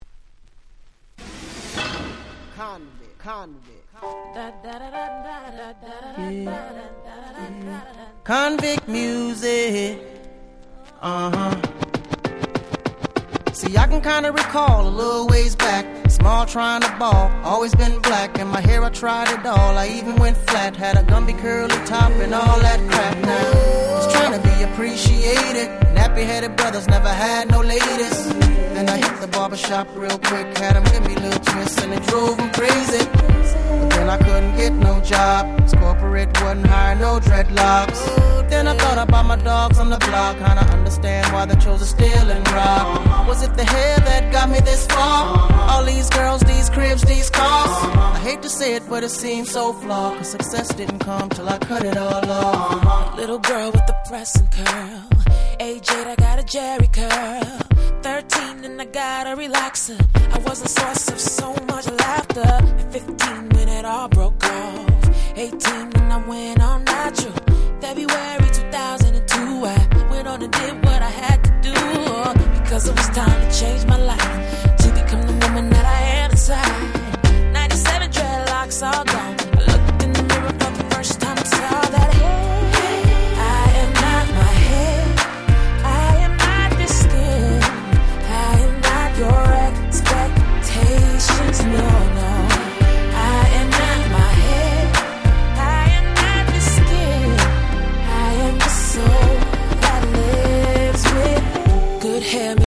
※試聴ファイルは別の盤から録音してございます。
06' Smash Hit R&B / Neo Soul !!
それぞれフロア向けのなかなか良いHip Hop Soulに仕上がっております。